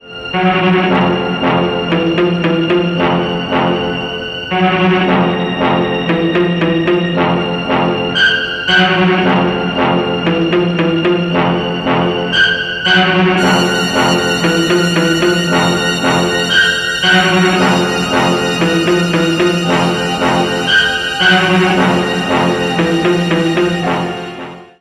Category: Scary Ringtones